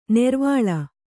♪ nervāḷa